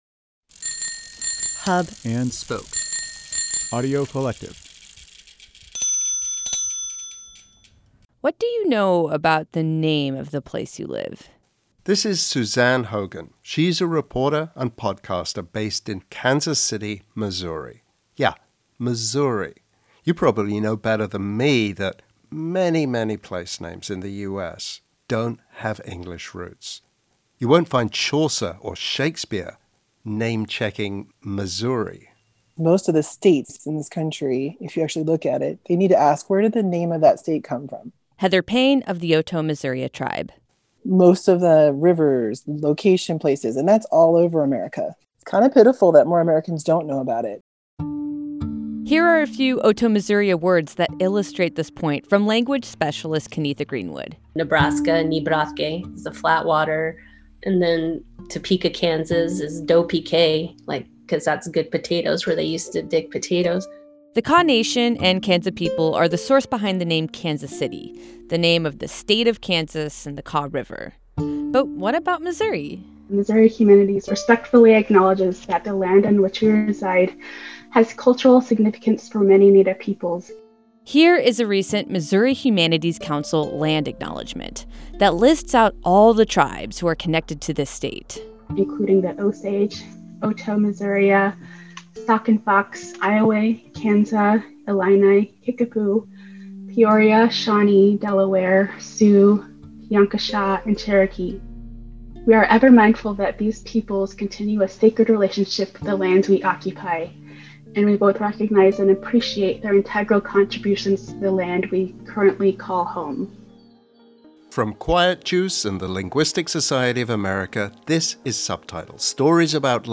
Music in this episode courtesy of the Otoe-Missouria Tribe.